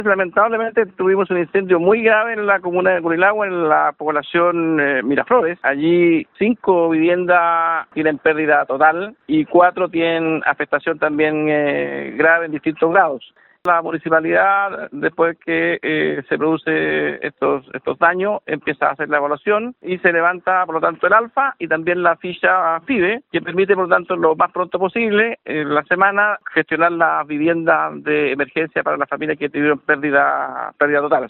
El delegado de la provincia de Arauco, Humberto Toro, detalló que en total fueron nueve las viviendas dañadas. Cinco tuvieron pérdida total y cuatro registraron daños de diversas proporciones.